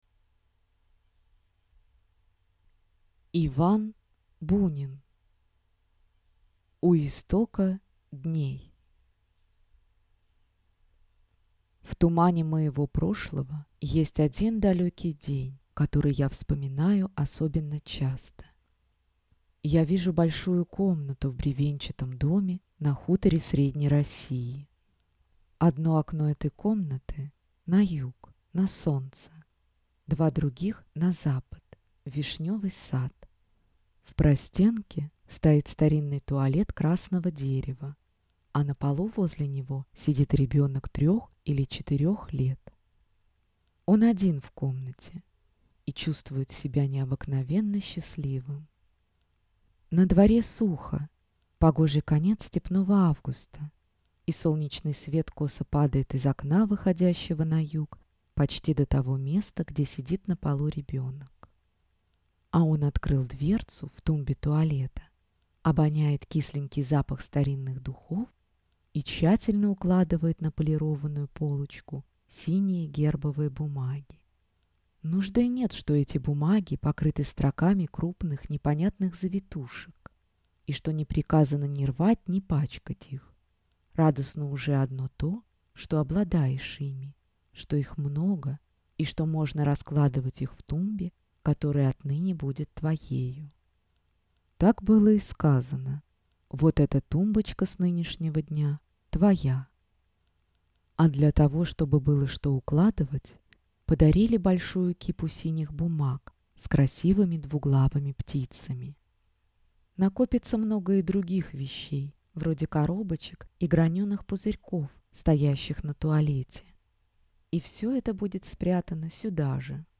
Аудиокнига У истока дней | Библиотека аудиокниг